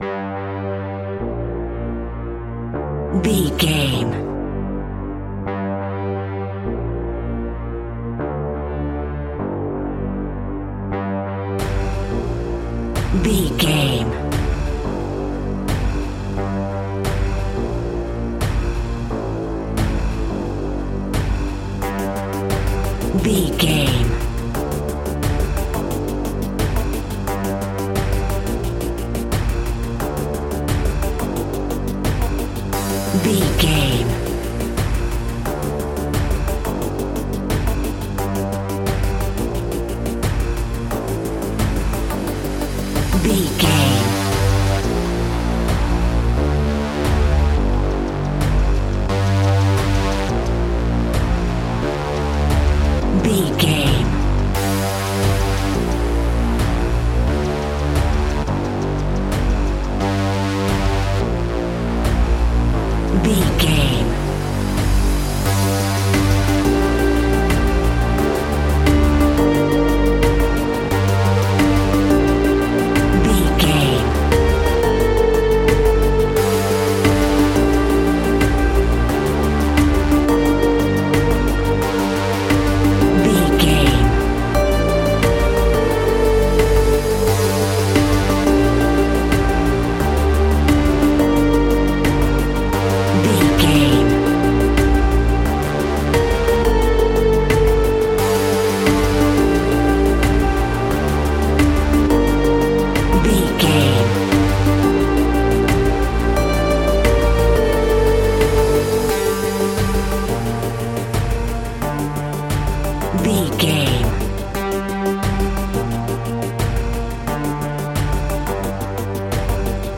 In-crescendo
Thriller
Aeolian/Minor
ominous
dark
eerie
synthesizer
drum machine
electronic music
Horror Synths